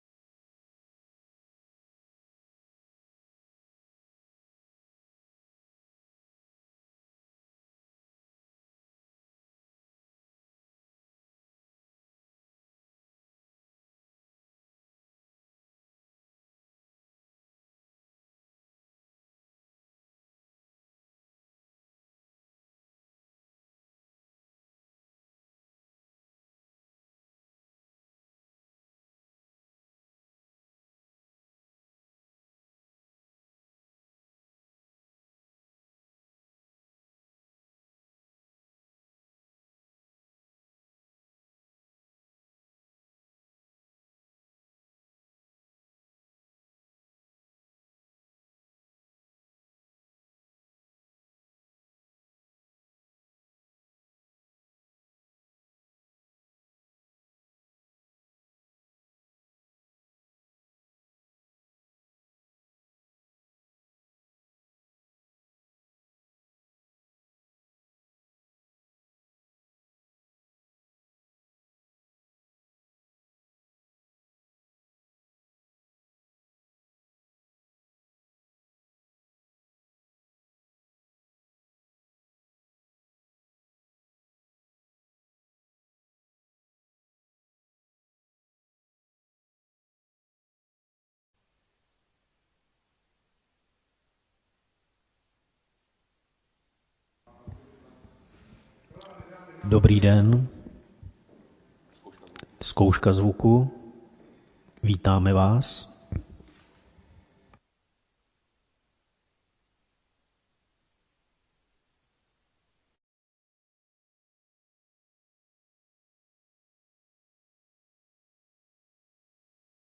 Město Varnsdorf: 22. zasedání Zastupitelstva města Varnsdorf Místo konání: Lidová zahrada, Karlova 702, Varnsdorf Doba konání: 27. ledna 2022 od 15:00 hod. 1.